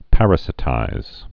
(părə-sĭ-tīz, -sī-)